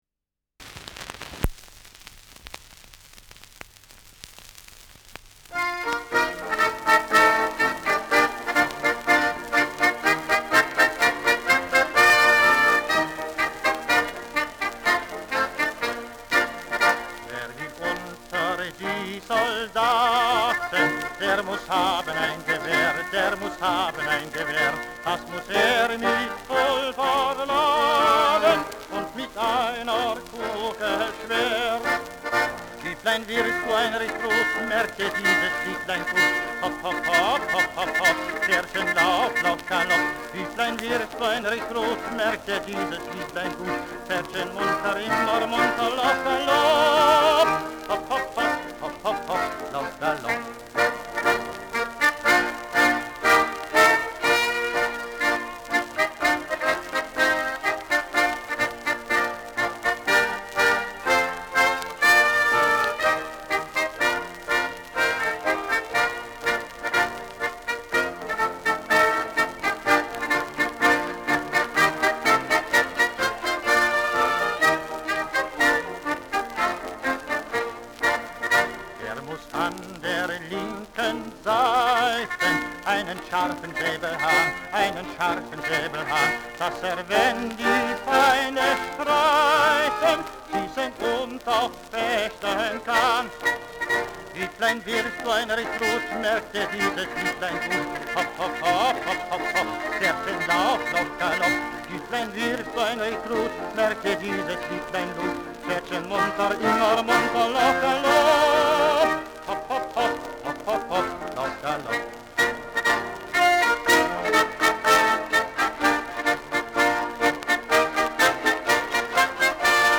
Schellackplatte
Tonrille: Kratzer 1 Uhr Stärker
Stärkeres Grundknistern
[unbekanntes Ensemble] (Interpretation)